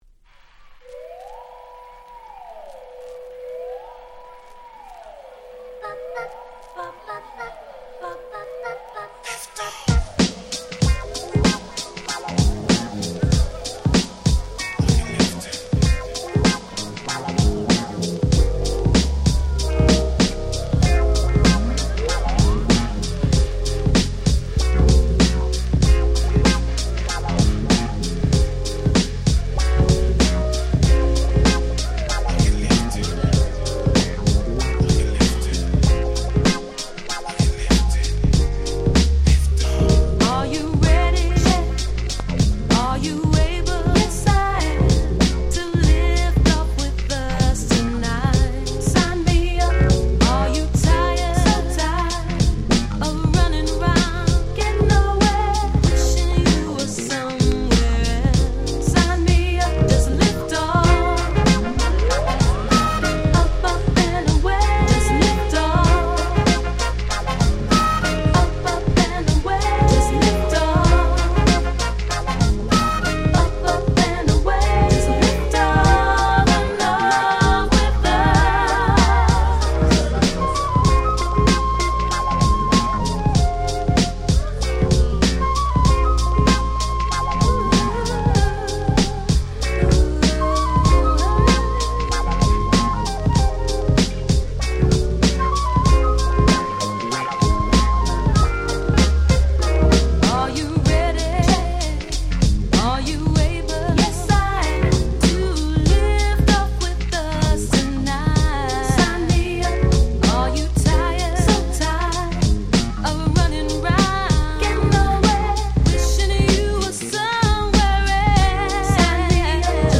Saxの音が気持ちの良い大変SmoothなUK Soulです！
アシッドジャズ　R&B